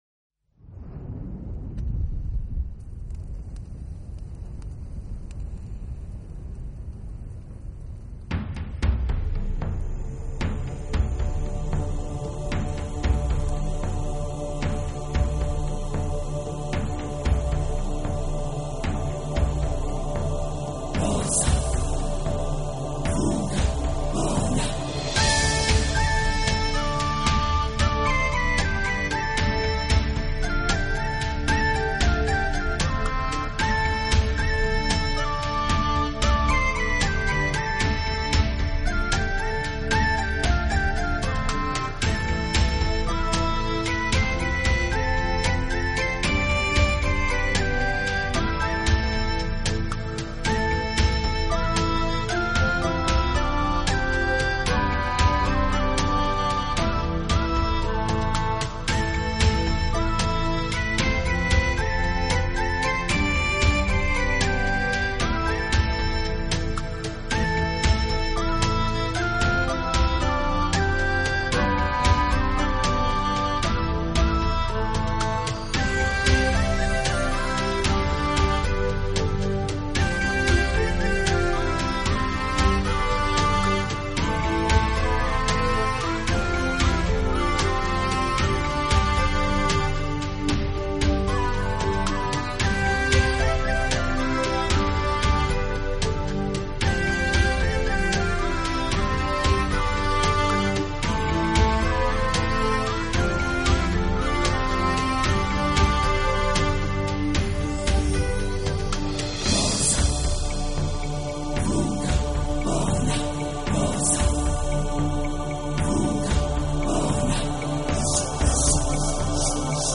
【新世纪音乐】